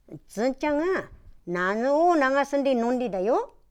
Aizu Dialect Database
Type: Single wh-question
Final intonation: Rising
WhP Intonation: Peak
Location: Aizumisatomachi/会津美里町
Sex: Female